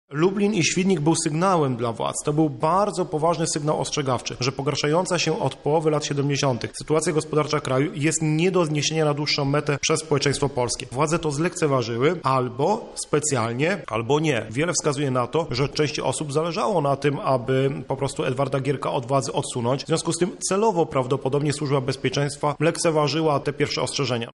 historyk